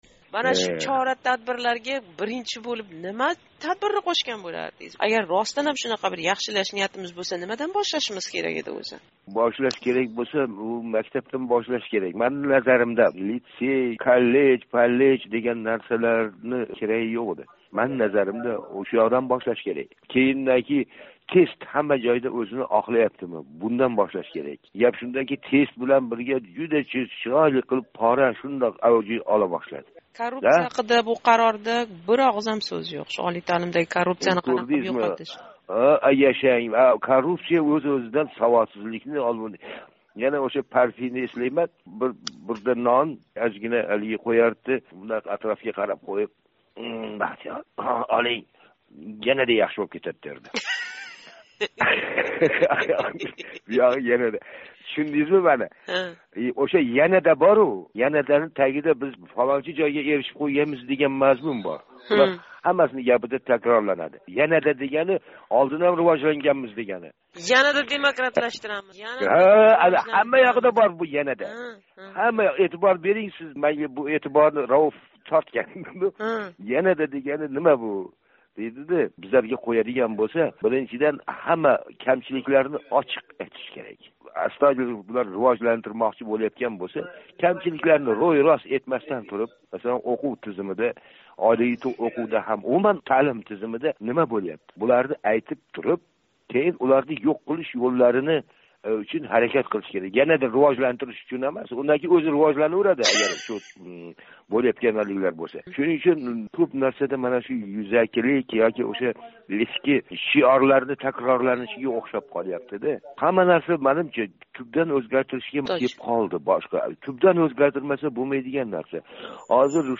Озодлик билан суҳбатда бу қарорга муносабат билдирган олий таълим соҳаси мутахассислари¸ қарорни олқишлаган ҳолда¸ унинг вазиятда фундаментал ўзгариш қилишига шубҳа билдирдилар.